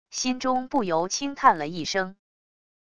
心中不由轻叹了一声wav音频生成系统WAV Audio Player